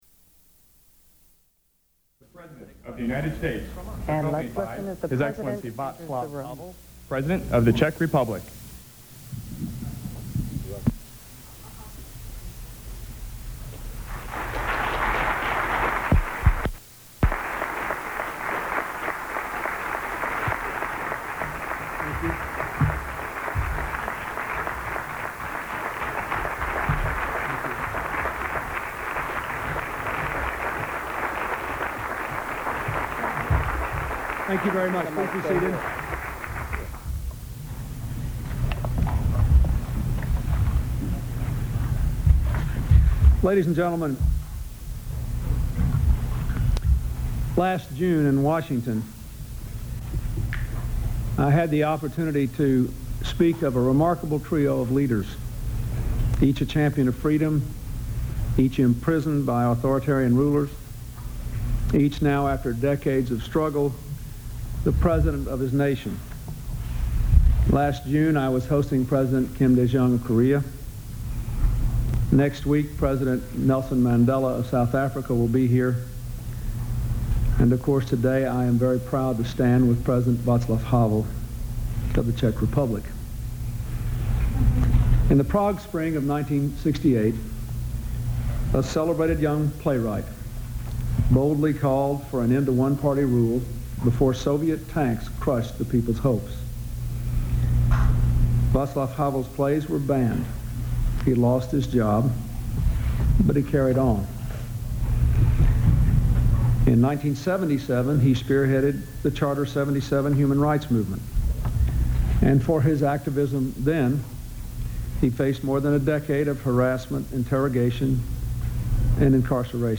U.S. President Bill Clinton and Czech Republic President Vaclav Havel hold a joint press conference